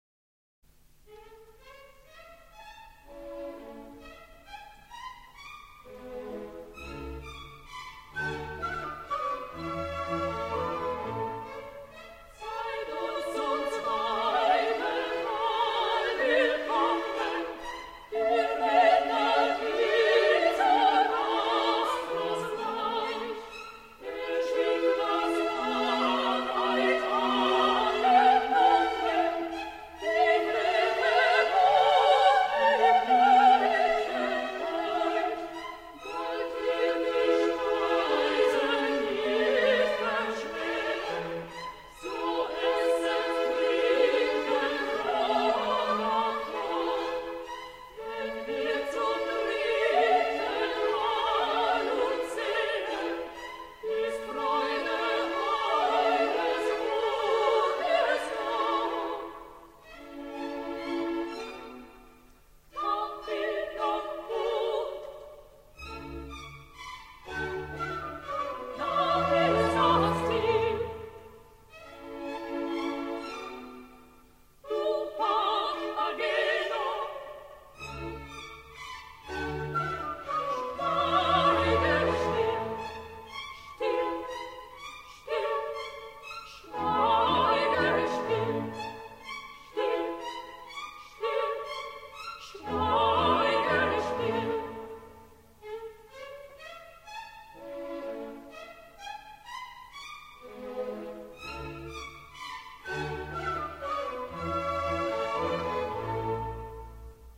Violin: Mozart: Magic Flute, Act II No. 16 (Terzett) – Orchestra Excerpts